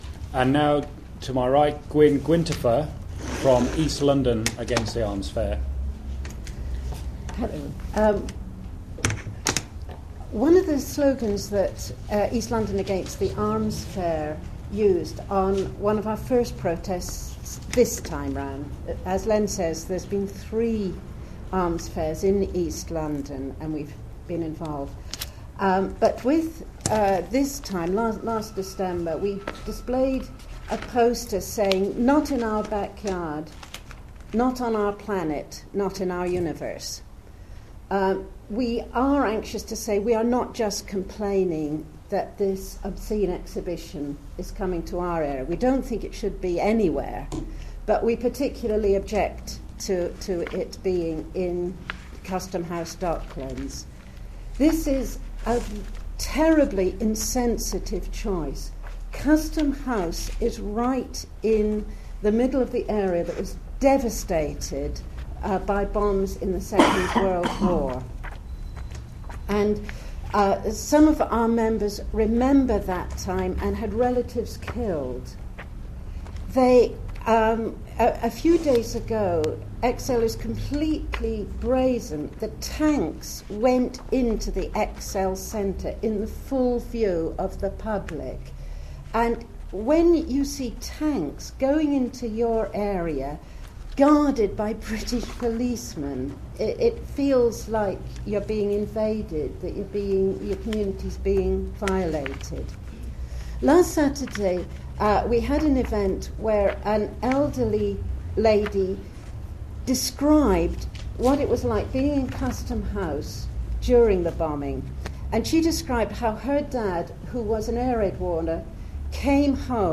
DSEi Audio - Press Conference led by CAAT - Fri 5th Sept
Five sections of audio from the press conference held today at Friends Meeting House in London.